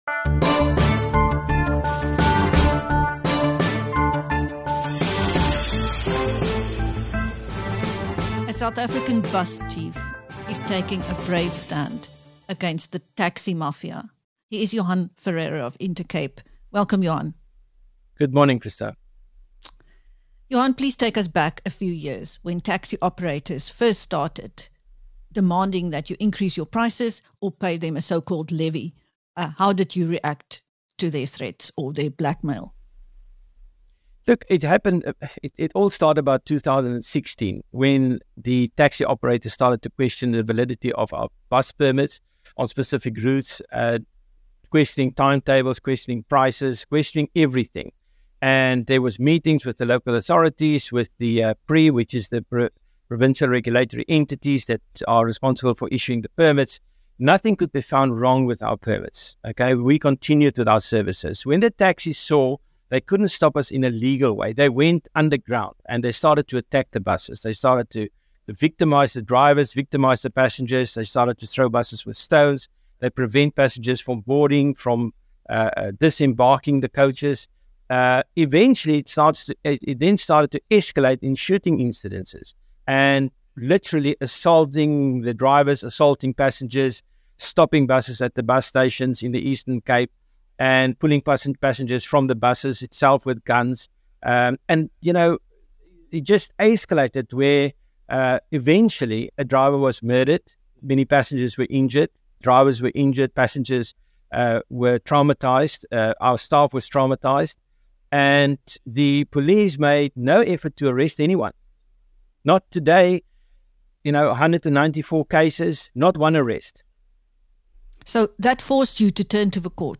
In this interview with BizNews